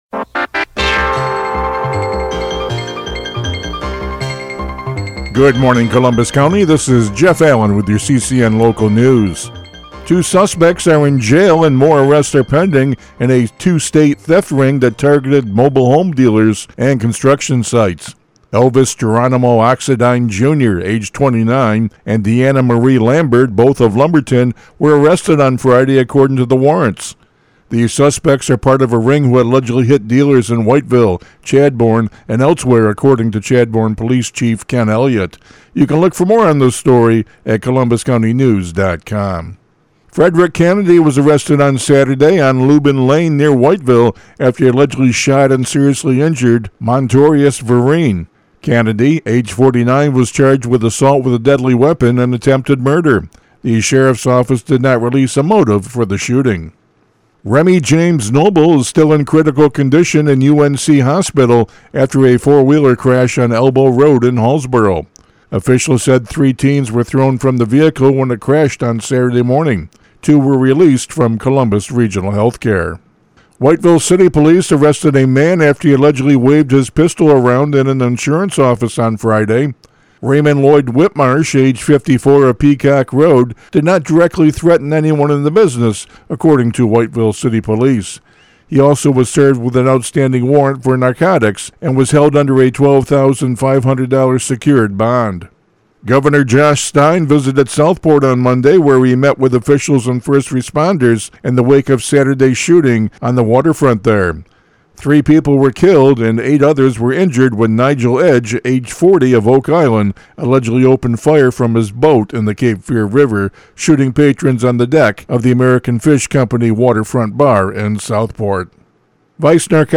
CCN Radio News — Morning Report for September 30, 2025